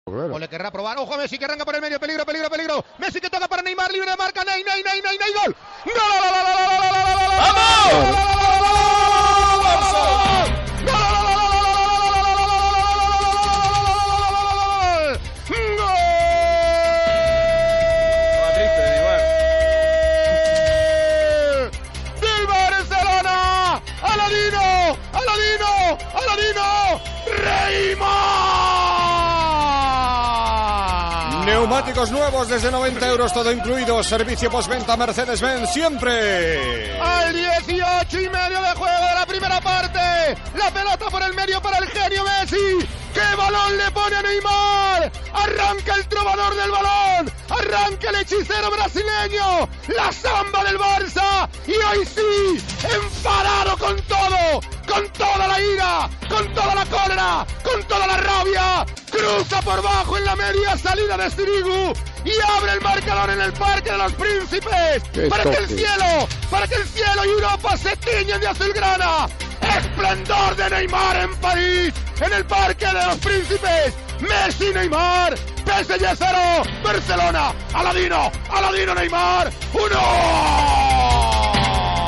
Transmissió del partit Paris Saint Germanin - Futbol Club Barcelona dels quarts de final de la Copa d'Europa de futbol masculí.
Esportiu